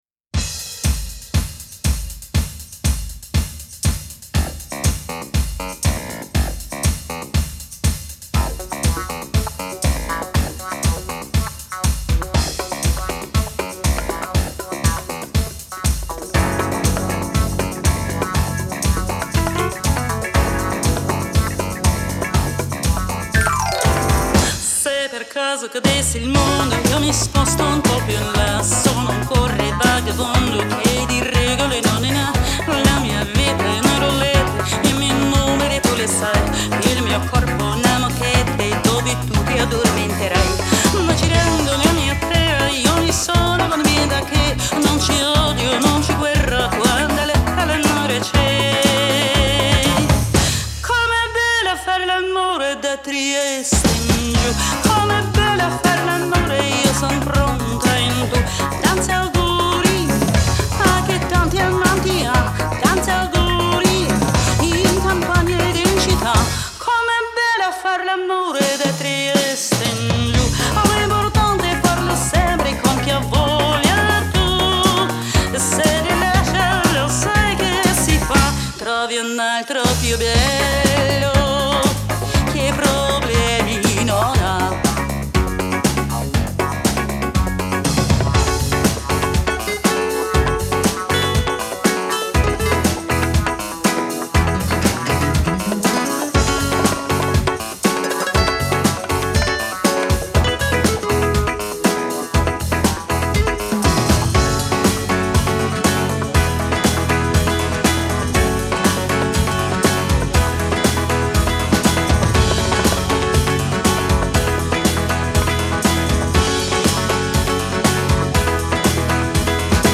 Комментарий инициатора: Любое популярное диско аля итальяно!